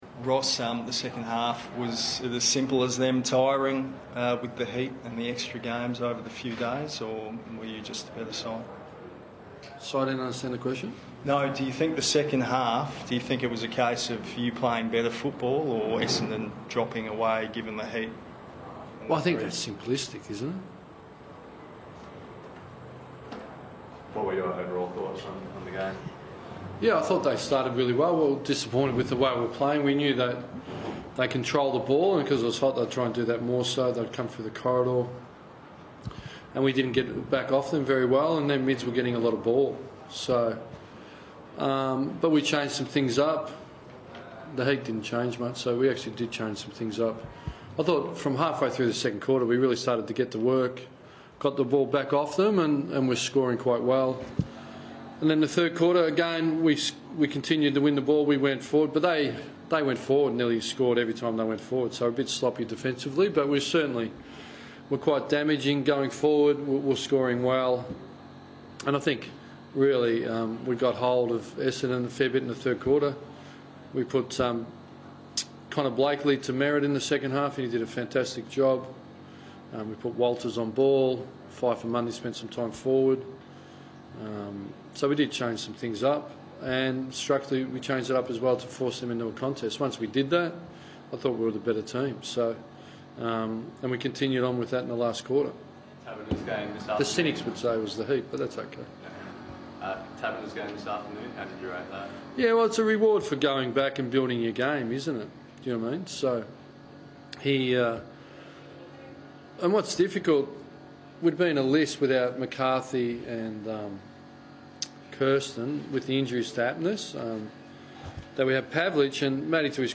Ross Lyon spoke to the media following the win over Essendon